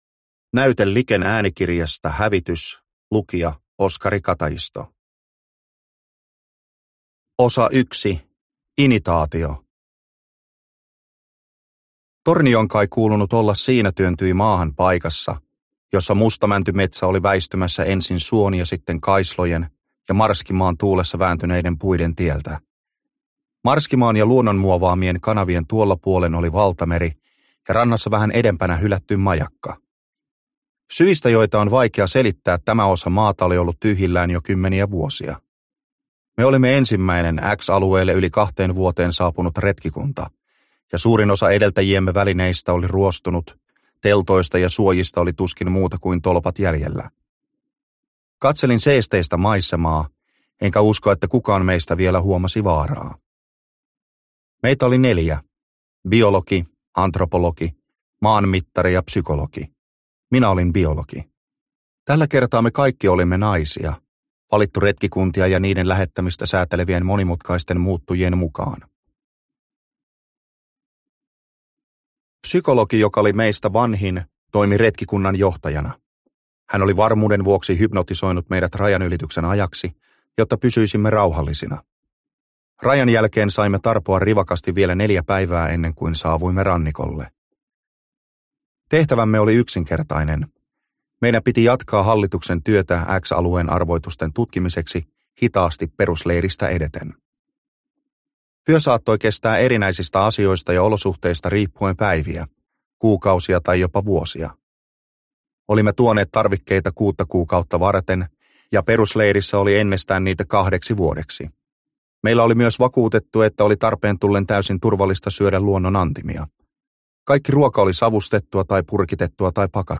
Hävitys – Ljudbok – Laddas ner